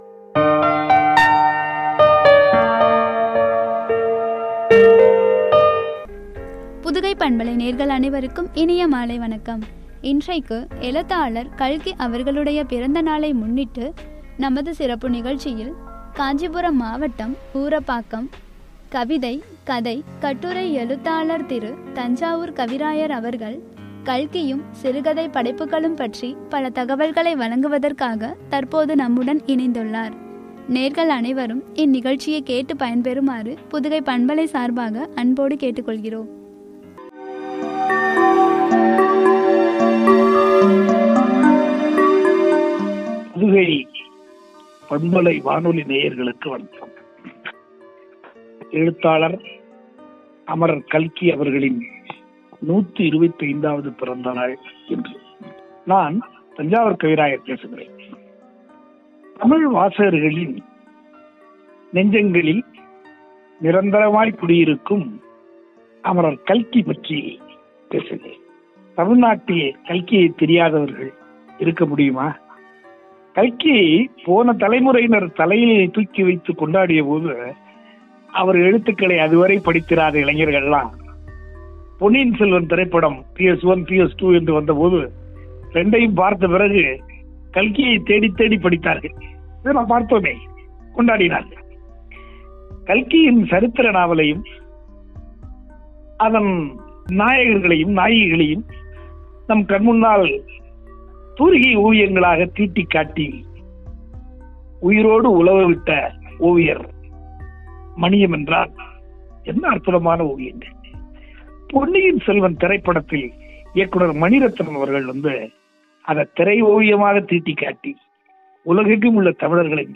சிறுகதை படைப்புகளும்” எனும் தலைப்பில் வழங்கிய உரையாடல்.